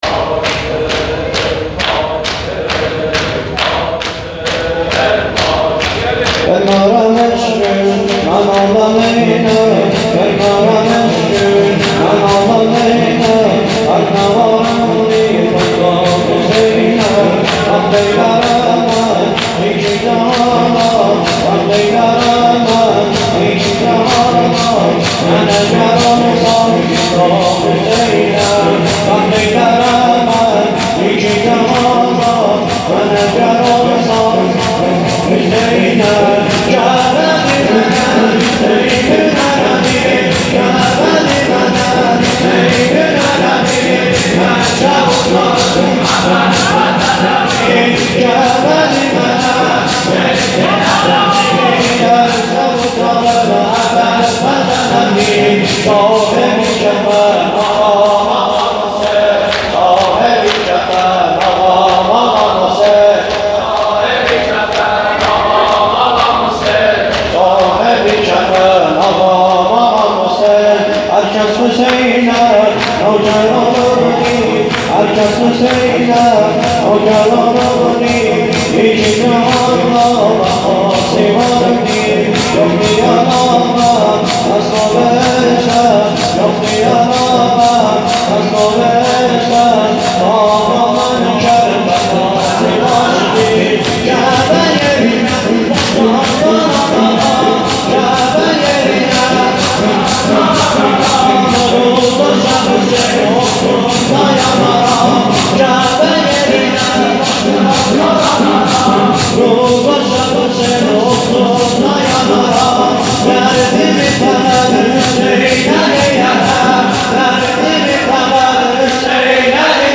شور ترکی
جلسه هفتگی پنجشنبه